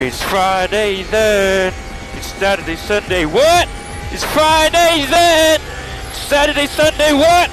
its friday thennnn Meme Sound Effect
This sound is perfect for adding humor, surprise, or dramatic timing to your content.